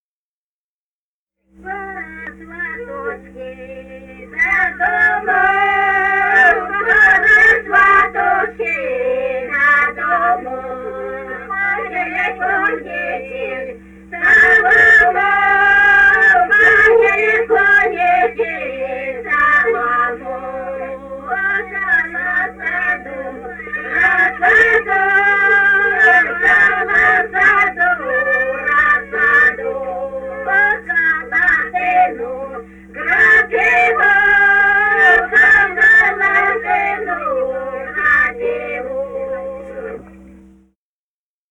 Русские народные песни Красноярского края.
«Пора, сваточки, до дому» (свадебная). с. Денисово Дзержинского района. Пела группа колхозниц